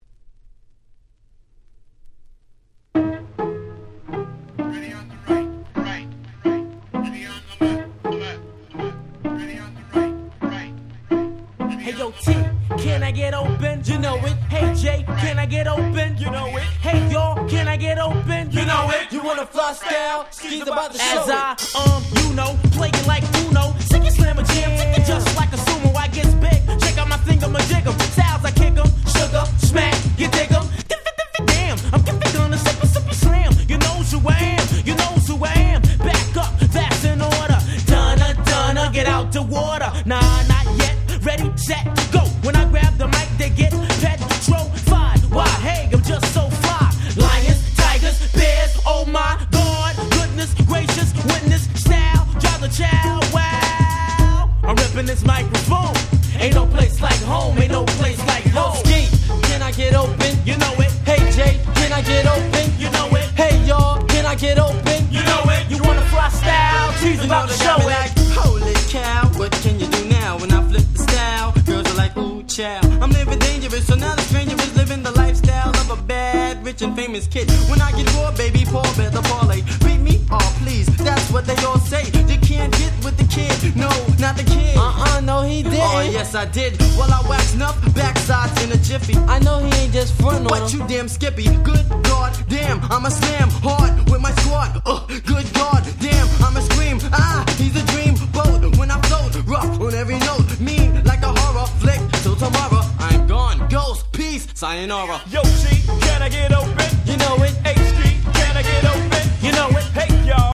93' Very Nice Hip Hop !!
イケイケなBeatと彼らのRapが非常に威勢の良い1曲！！
90's New School ニュースクール ジェイジー Boom Bap ブーンバップ